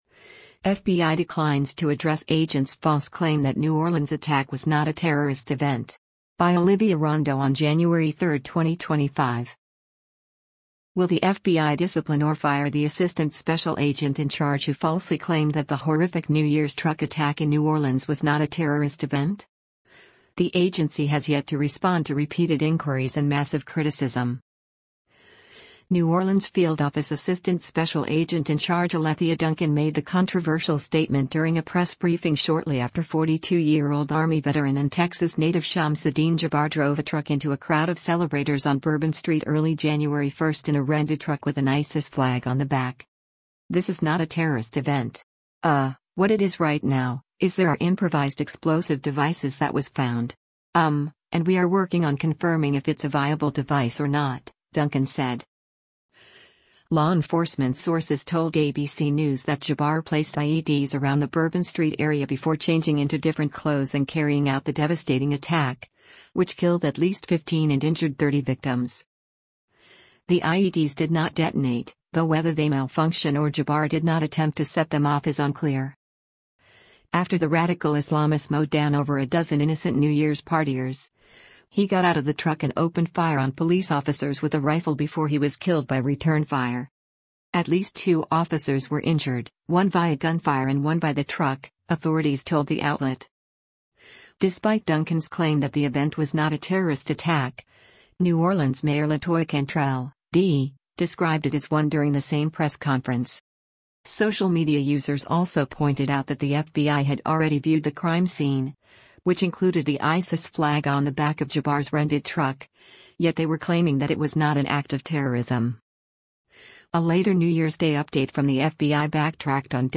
speaks to the media during a press conference